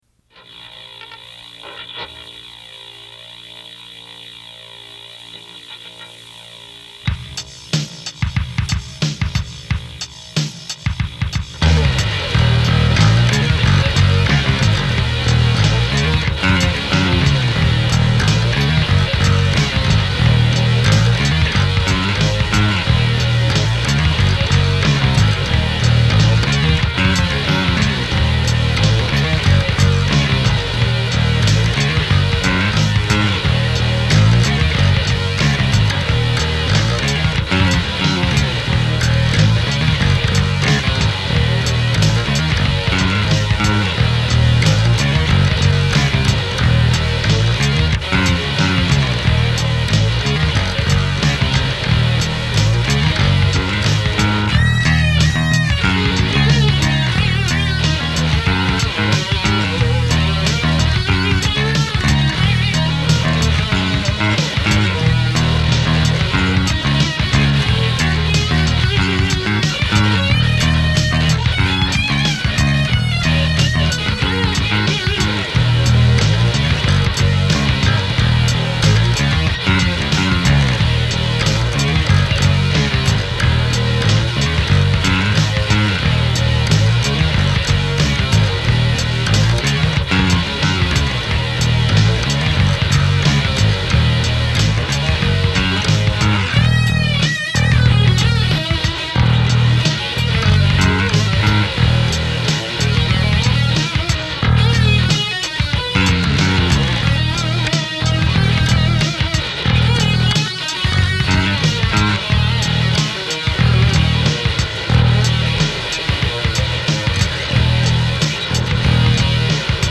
This is my first multitrack recording, in 1987.  I hadn't bought a 4-track yet, so this is two tape decks linked together, with some funky adapters to splice the audio cables such that I could overlay a new instrument while recording from one tape deck to the other.
Bass, guitar, drum machine: